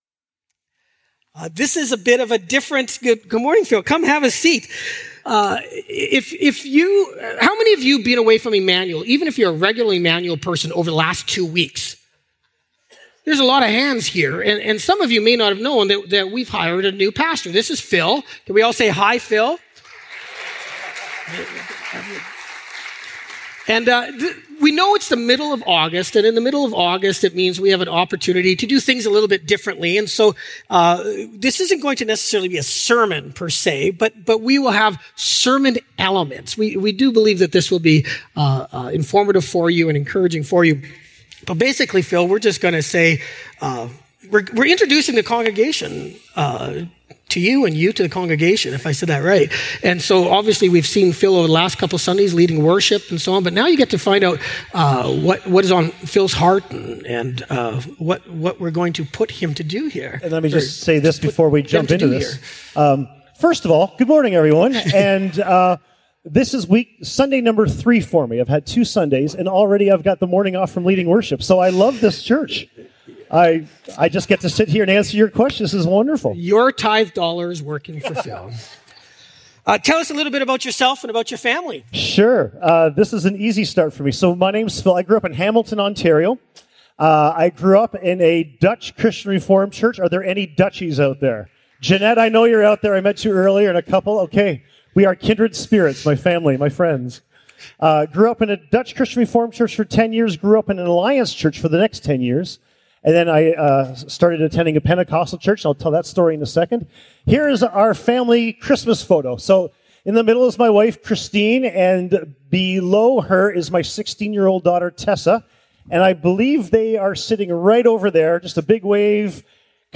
Sermons | Emmanuel Church